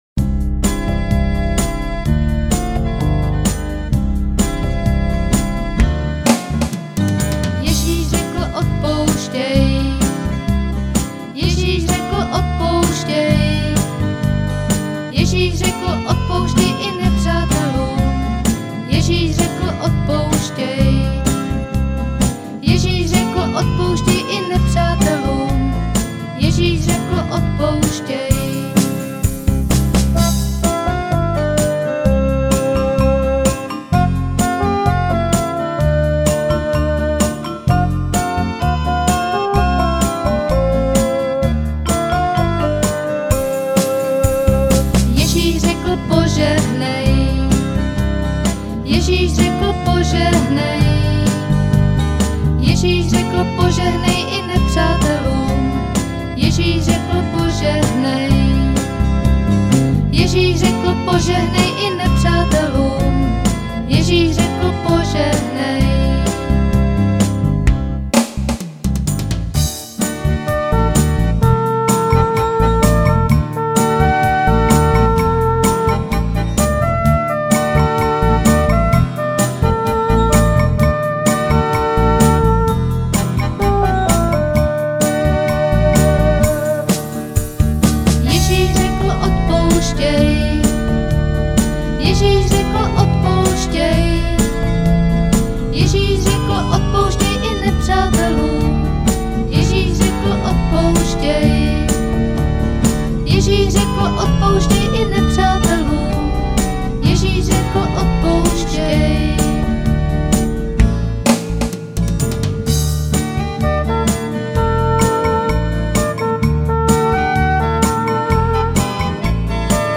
Křesťanské písně
Písně ke chvále a uctívání